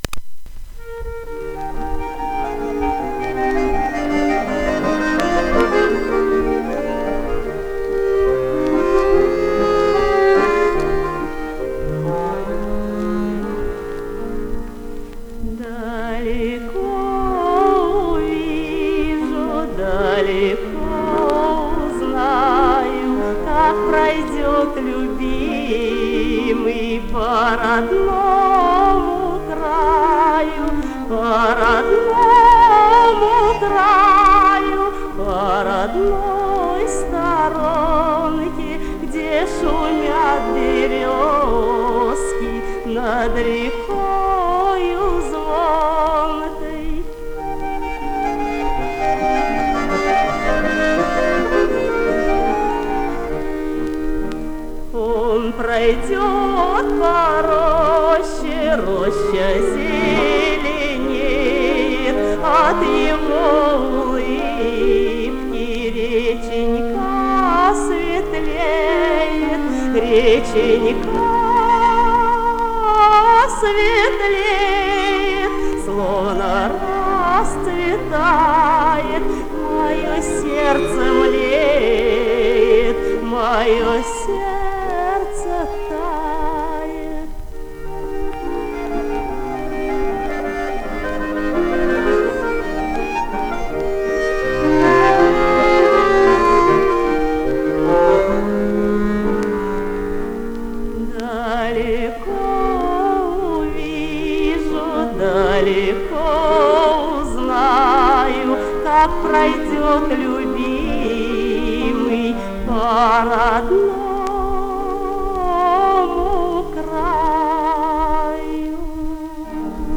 баяны